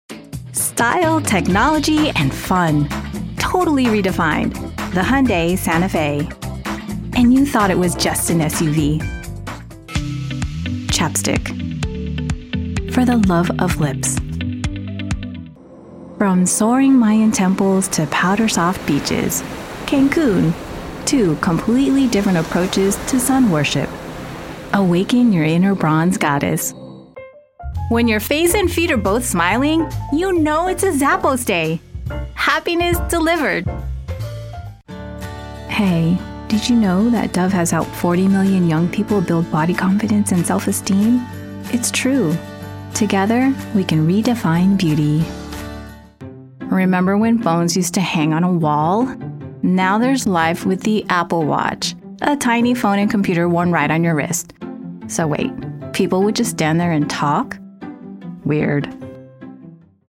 Demo comercial
My voice ranges from the mid-20s to mid-30s and is a very neutral American accent.
My signature voice sound is bright, current, youthful, upbeat, Millennial, and Gen Z relatable.
I have a professional, audio engineer-approved studio and can connect via Zoom or Source-Connect or any platform of your choice for a live session.